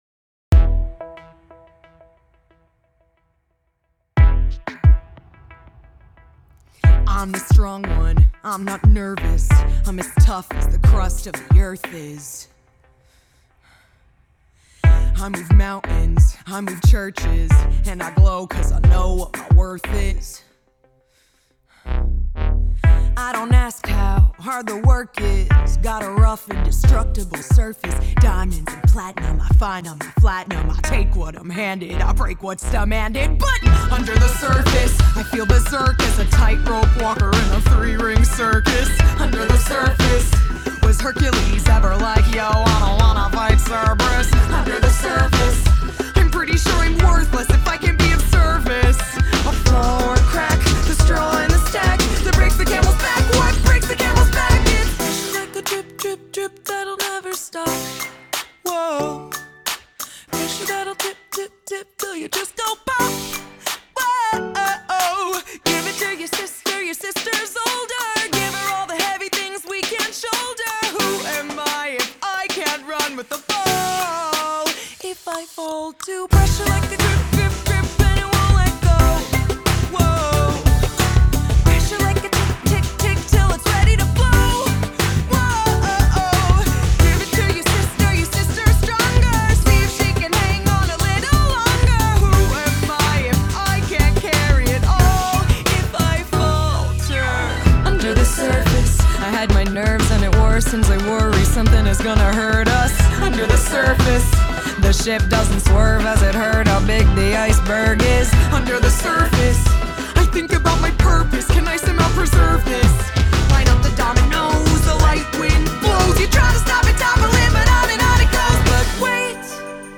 reggaeton